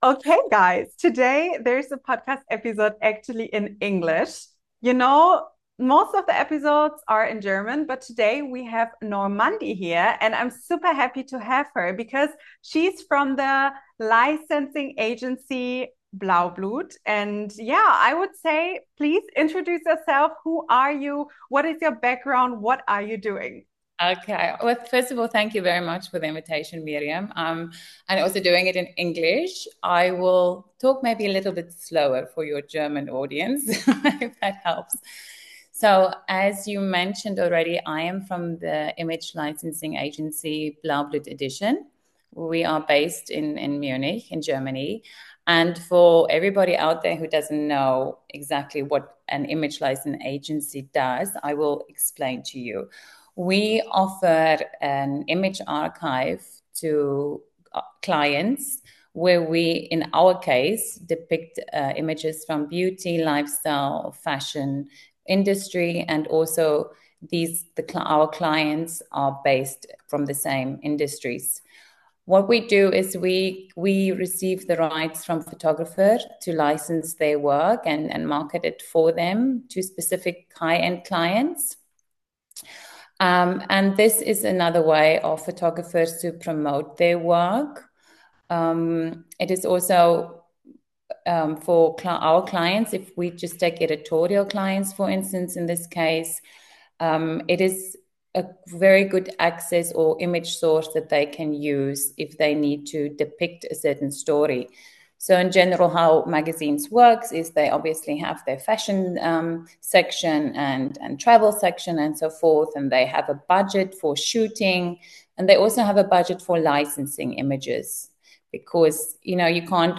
#275: SO kommst du in die VOGUE - Interview